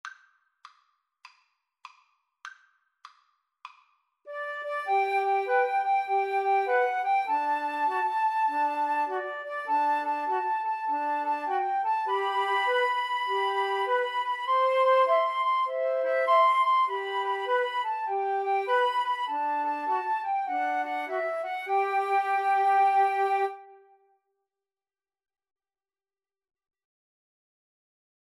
Free Sheet music for Flute Trio
G major (Sounding Pitch) (View more G major Music for Flute Trio )
With a swing!